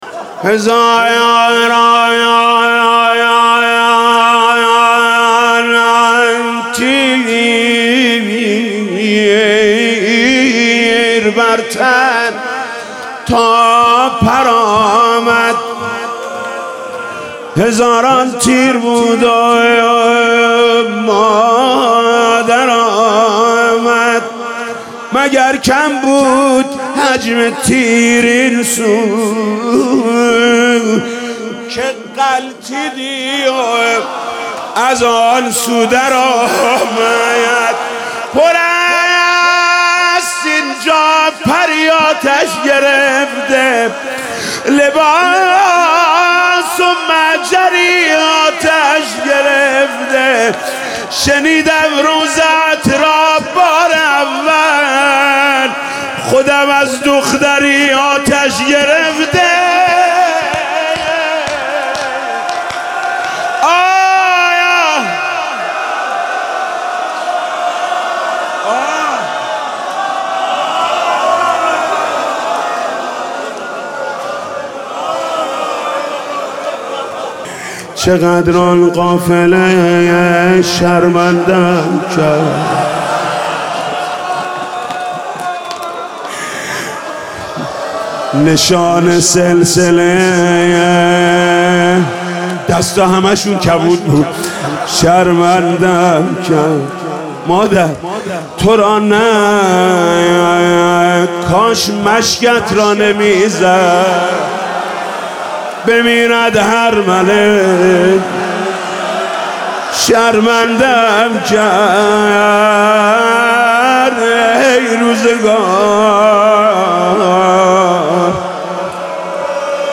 روضه خوانی حاج محمود کریمی به مناسبت وفات حضرت ام البنین (ع) - تسنیم
همزمان با سالروز وفات حضرت ام البنین (ع) صوت روضه خوانی حاج محمود کریمی به همین مناسبت منتشر می شود.